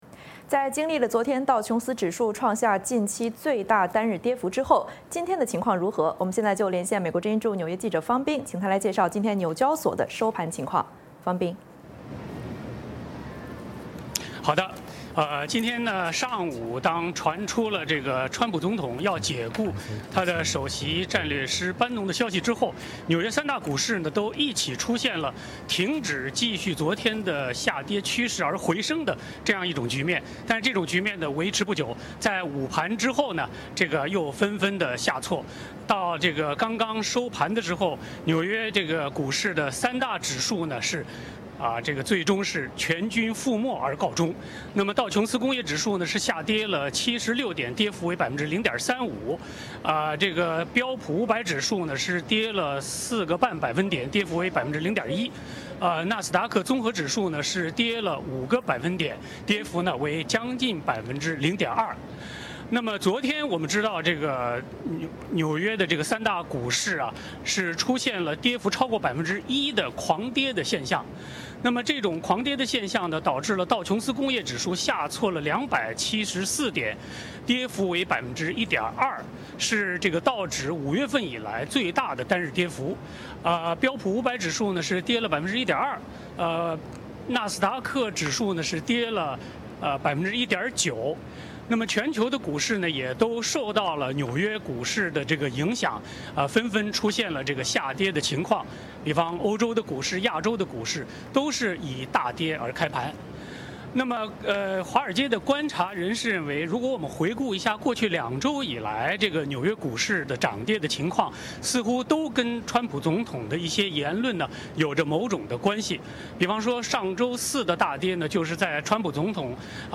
VOA连线：解雇班农未挽回股市下跌趋势